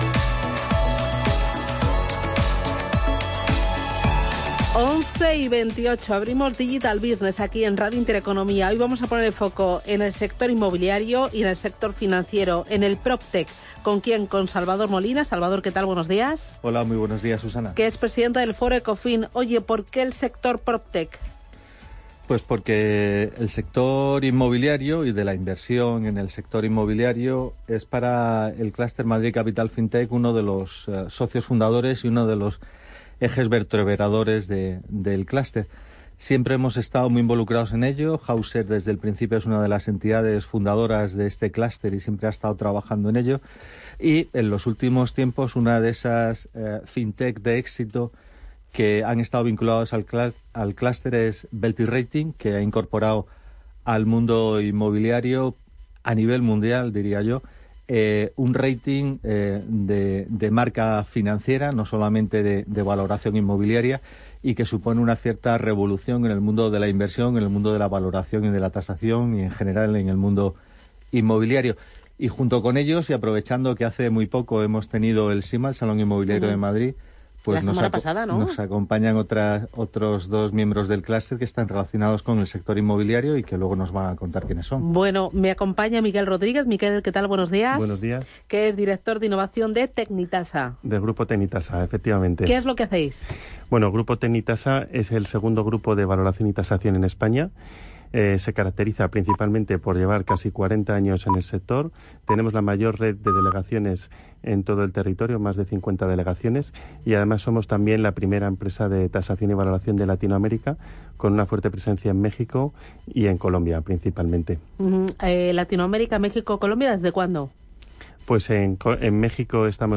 El programa Digital Business recibió a directivos de Tecnitasa, Mr. Houston y Veltis Rating